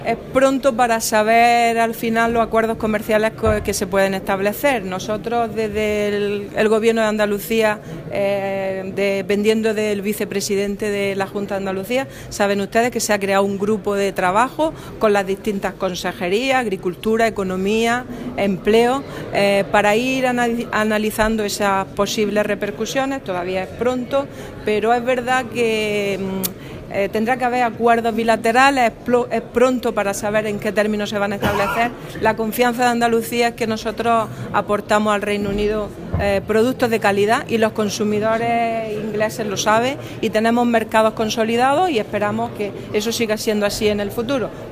Declaraciones de Carmen Ortiz sobre el impacto del Brexit en el sector agroalimentario